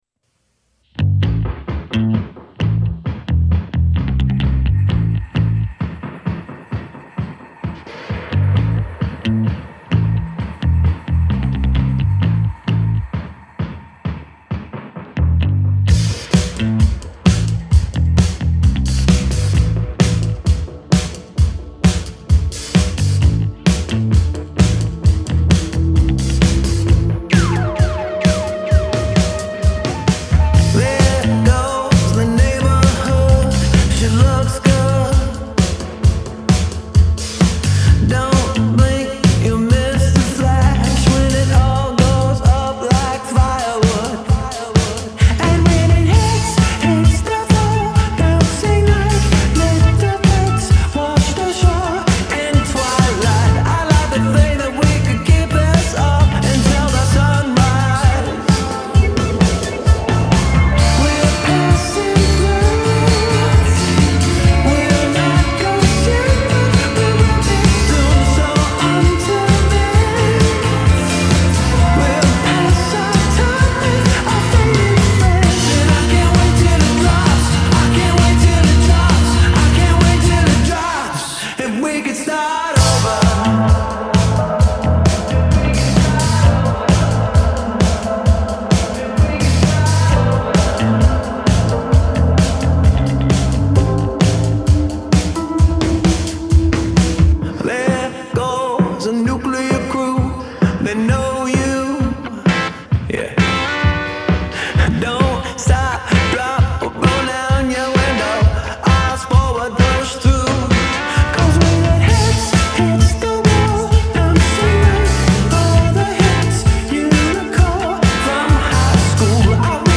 Tag: psychedelic
Part disciplined indie rocker, part psychedelic freakout
an Atlanta-based trio